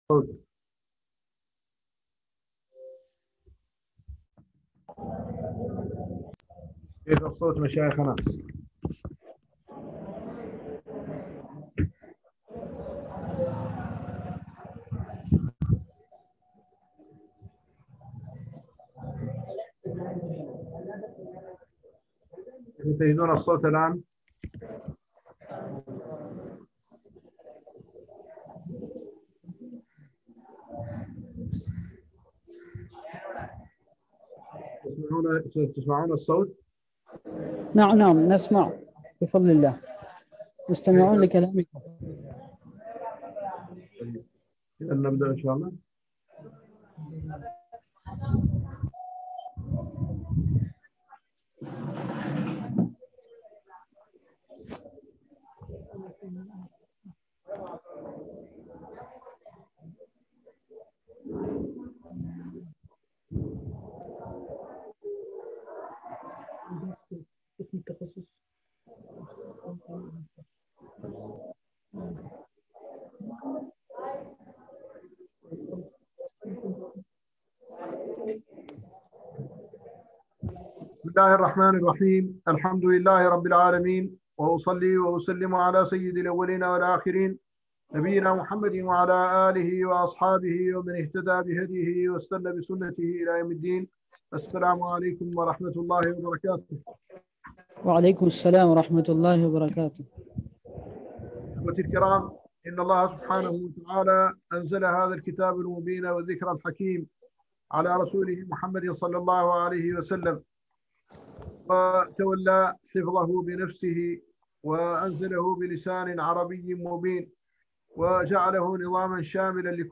درس في تدبر القرآن و علومه لصالح قسم التخصص في التفسير وعلوم القرآن جامعة مليبار -كيرالا- بالهند